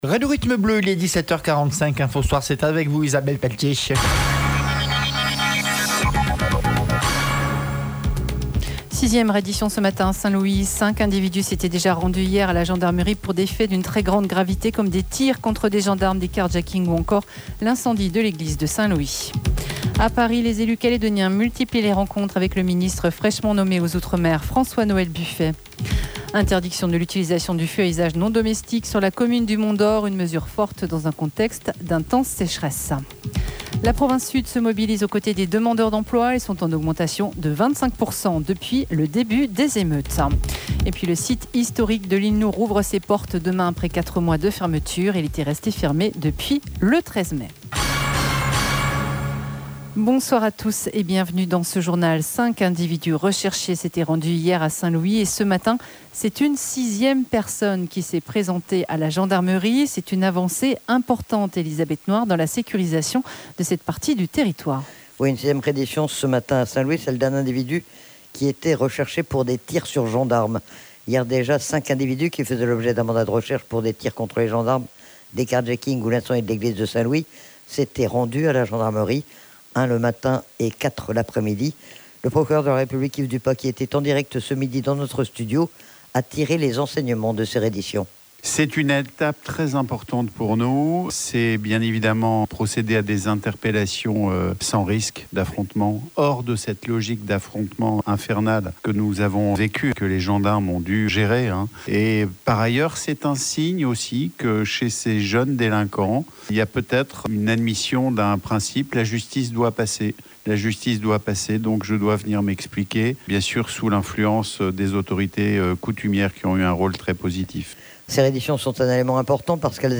JOURNAL : INFO SOIR 01/10/24